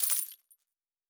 Coins 03.wav